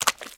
STEPS Swamp, Walk 02.wav